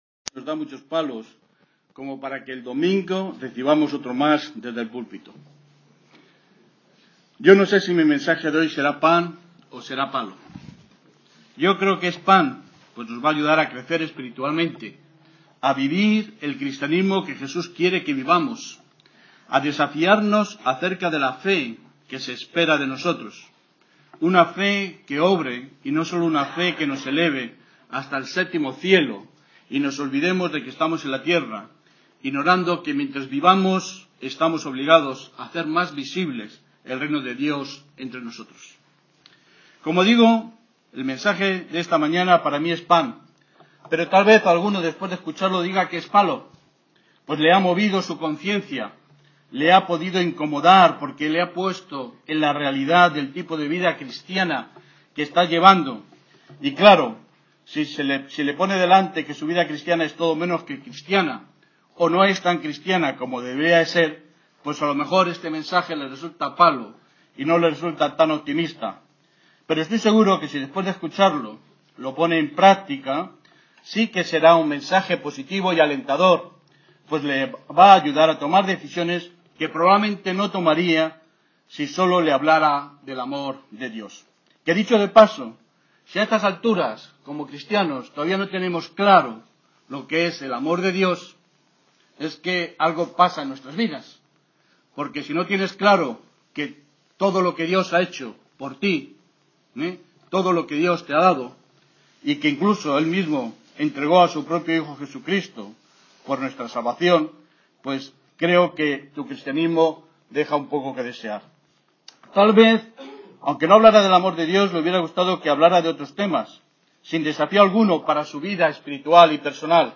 Series: Mensajes